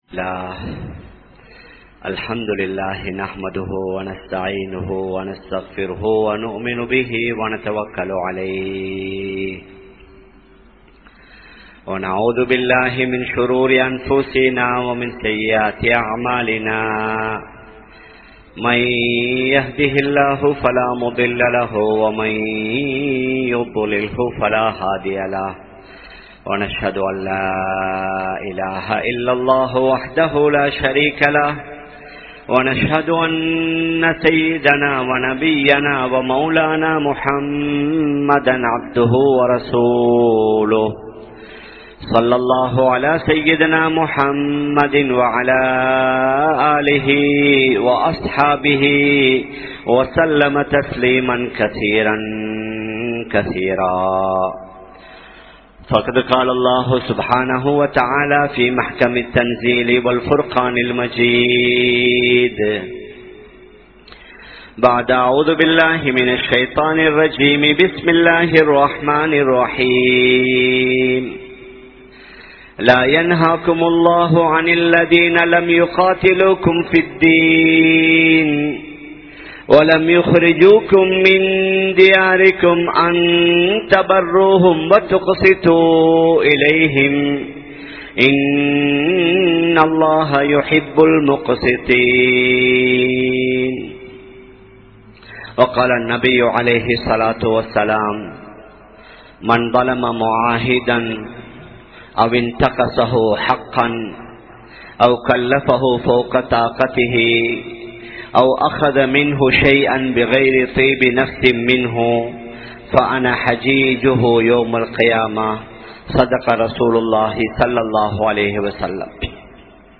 Samuhathoadu Inainthu Vaalntha Nabi(SAW)Avarhal (சமூகத்தோடு இணைந்து வாழ்ந்த நபி(ஸல்)அவர்கள்) | Audio Bayans | All Ceylon Muslim Youth Community | Addalaichenai
Town Jumua Masjith